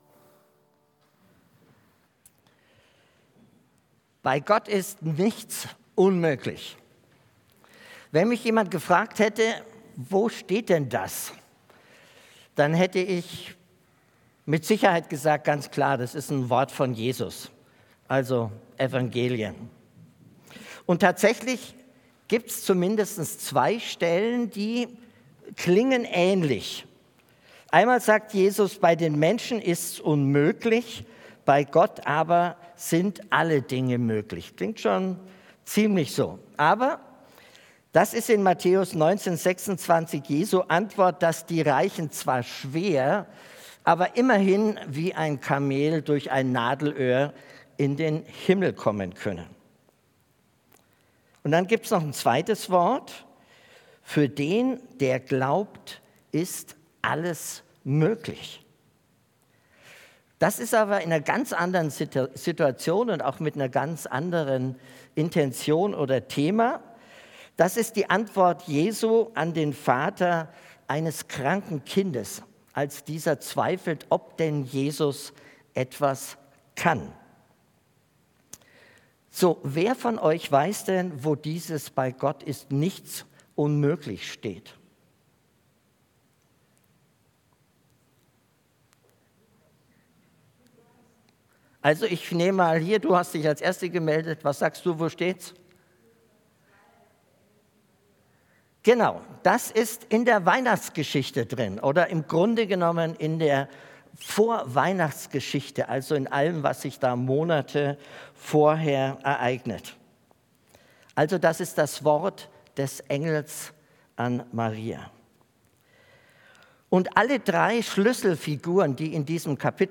Predigt Die Umkehr-Revolution: Weihnachten für die Übersehenen - 4.